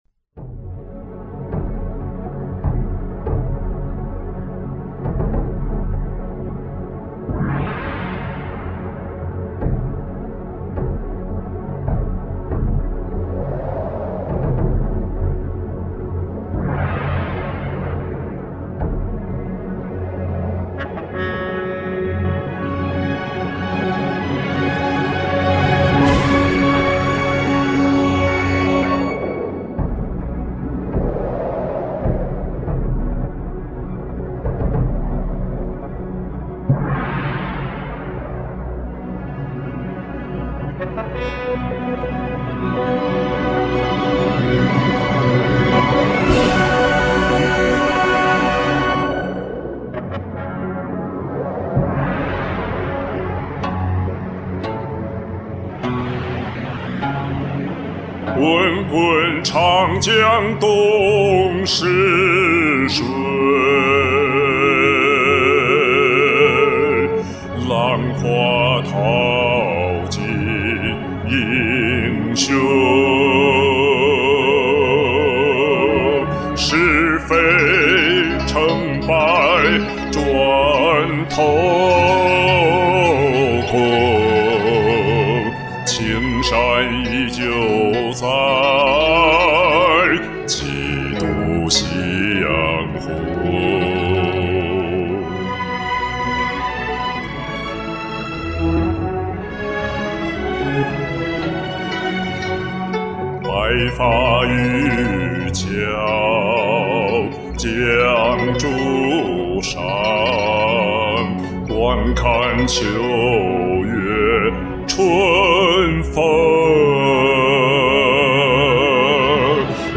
难歌，演唱得很到位！
歌声磅礴豪迈，低音好厉害！
好享受，低音非常稳定，音色明亮！